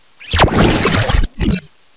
cries
polteageist.aif